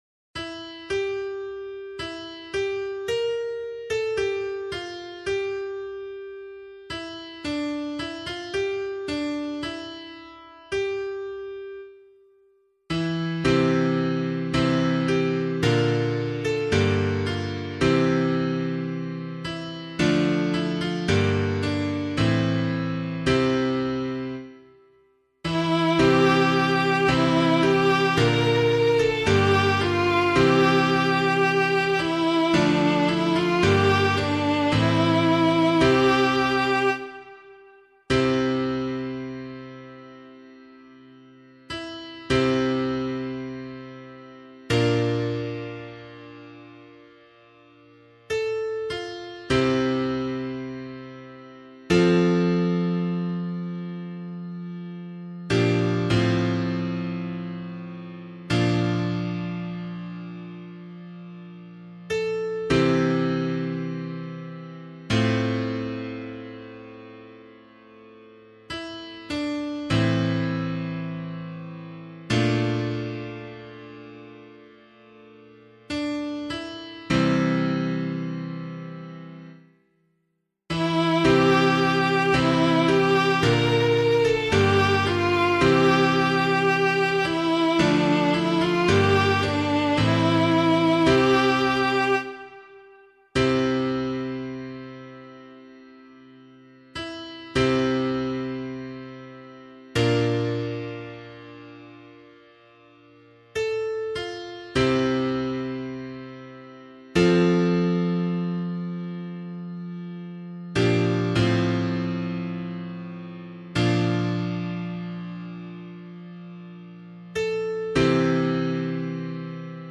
024 Easter 2 Psalm C [APC - LiturgyShare + Meinrad 7] - piano.mp3